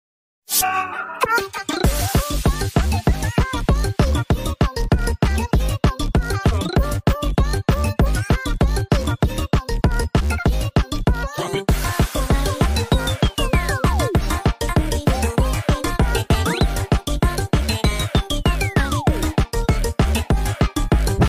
Original clip was sped up sound effects free download